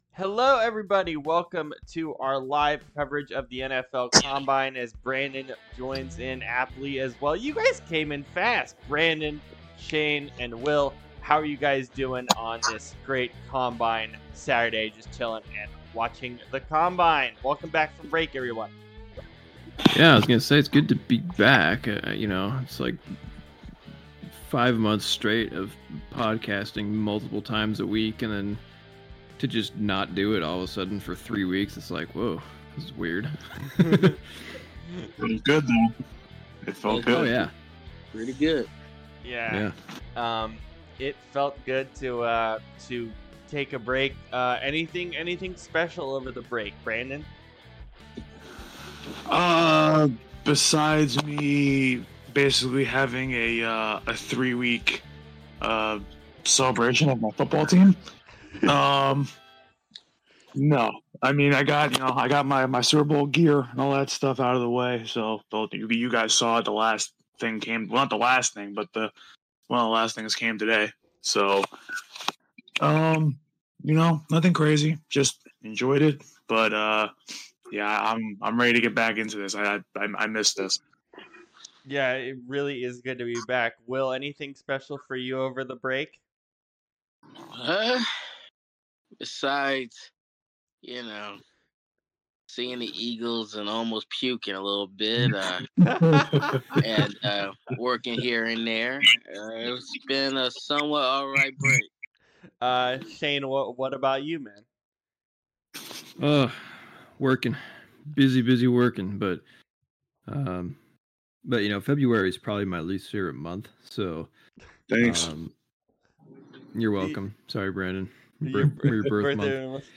Chargers Cast: NFL Combine Live Reaction – SportsEthos